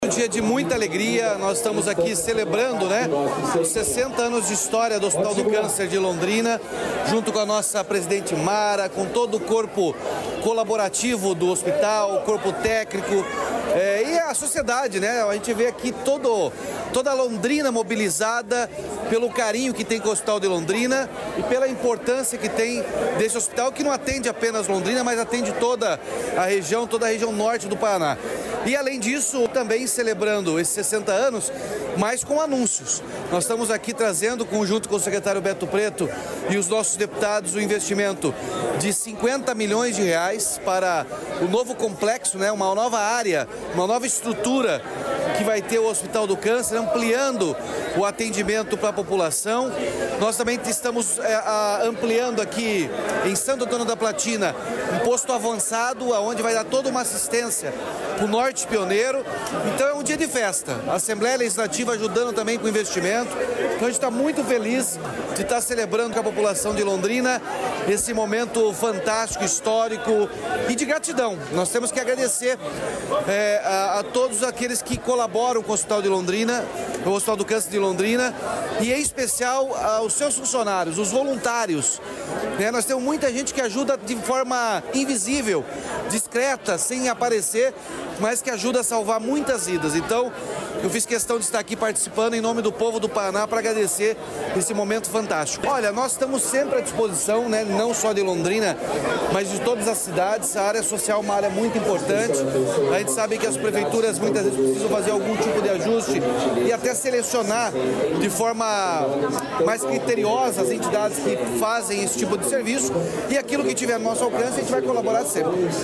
Sonora do governador Ratinho Junior sobre o investimento no hospital de Londrina